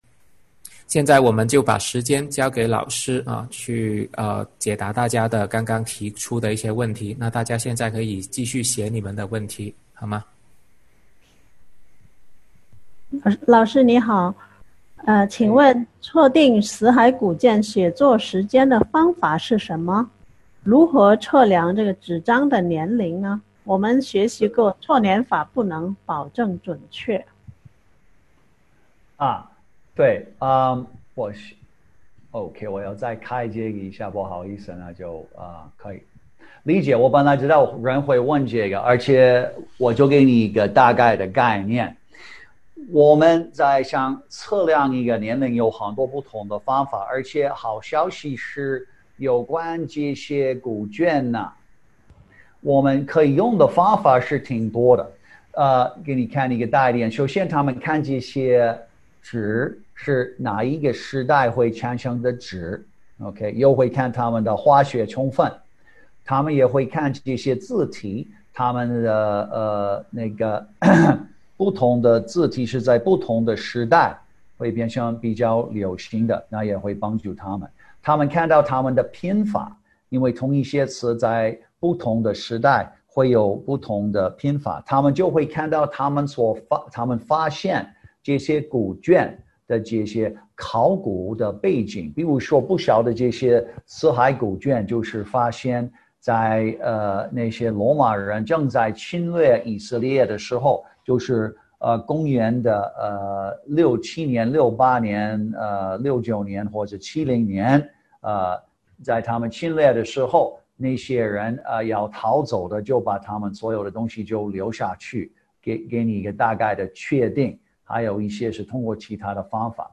《旧约预言的应验》讲座直播回放